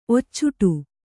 ♪ occuṭu